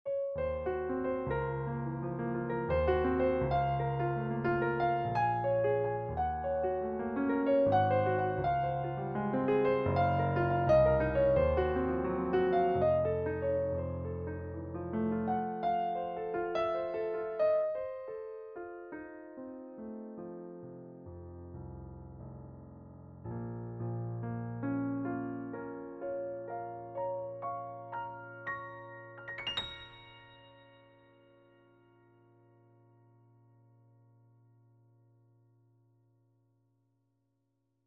SK-EX Competition Grand
mellow-brahms.mp3